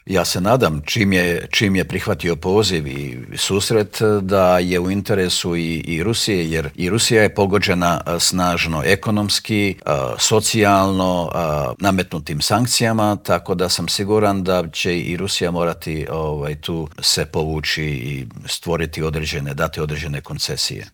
ZAGREB - U Intervjuu tjedna Media servisa gostovao je ministar vanjskih i europskih poslova Gordan Grlić Radman s kojim smo proanalizirali poruke poslane iz Davosa i Bruxellesa, kako od američkog predsjednika Donalda Trumpa i ukrajinskog predsjednika Volodimira Zelenskog tako i od premijera Andreja Plenkovića.